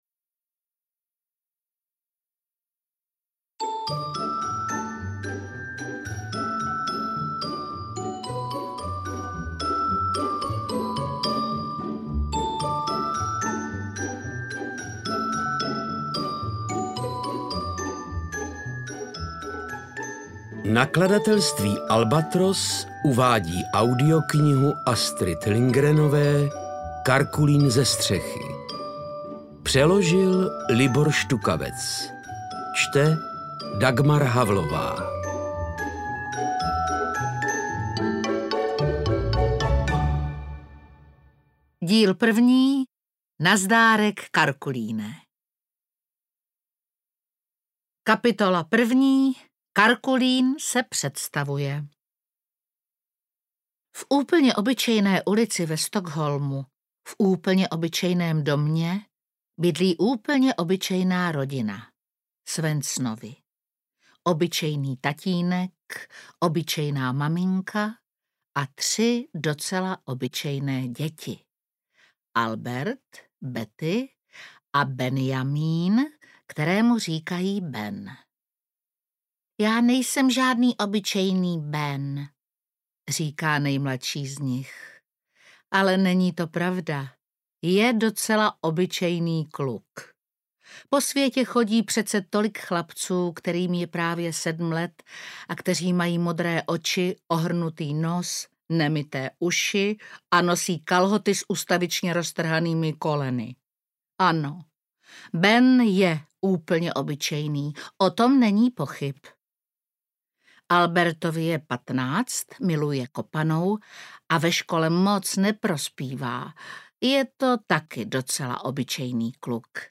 Interpret:  Dagmar Havlová
AudioKniha ke stažení, 18 x mp3, délka 7 hod. 2 min., velikost 385,2 MB, česky